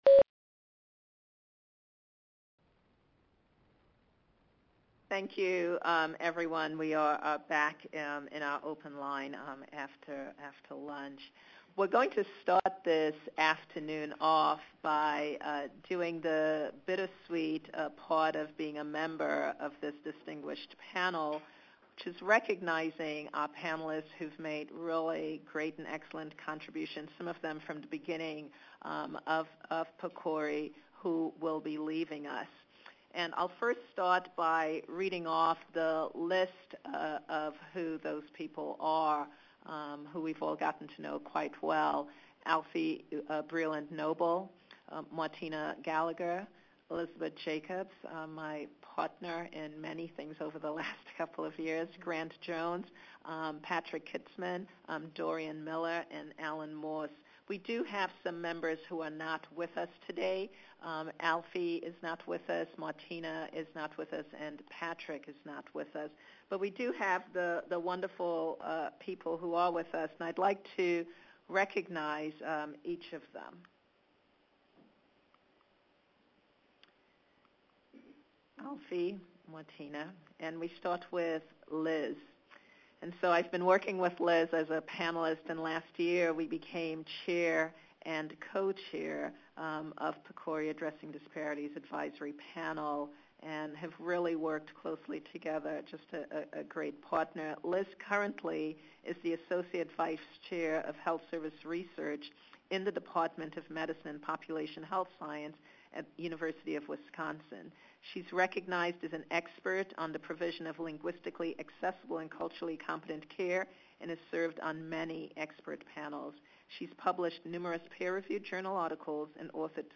Part 1: Listen to the Day 1 Teleconference Audio Recording